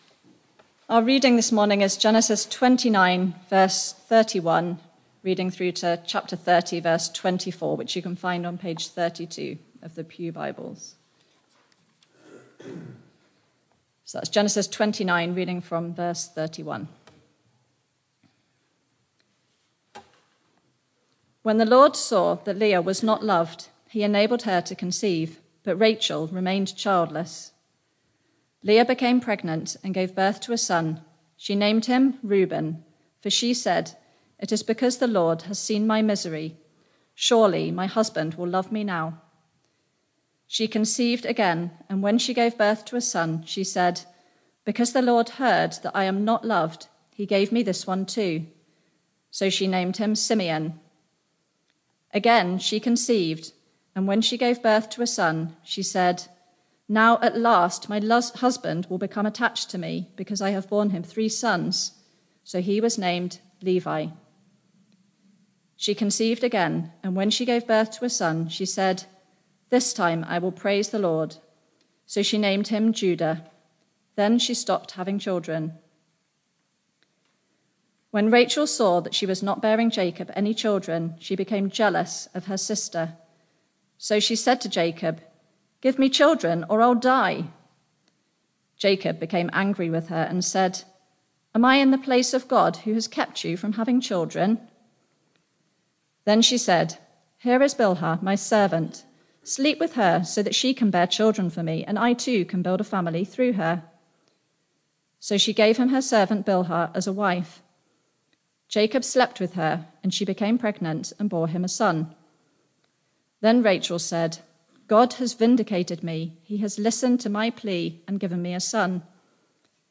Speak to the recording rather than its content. Barkham Morning Service